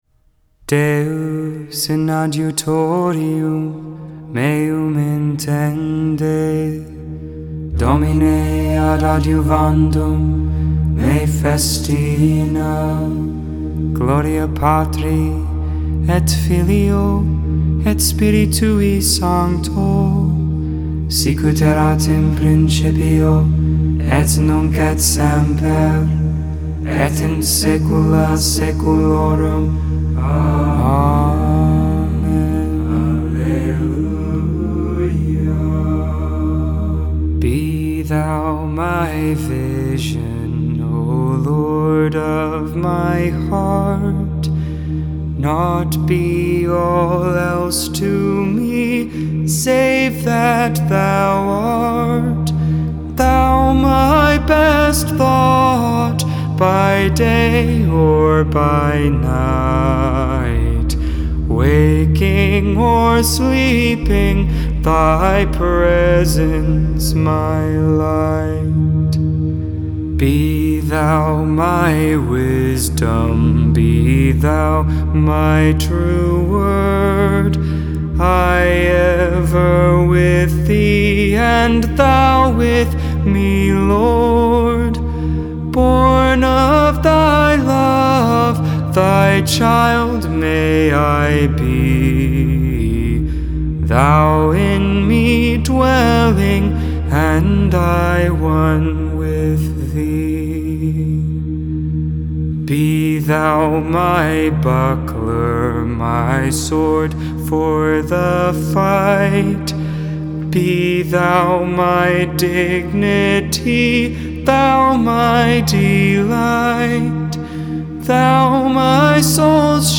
Benedictus (English, Tone 8, Luke 1v68-79) Intercessions: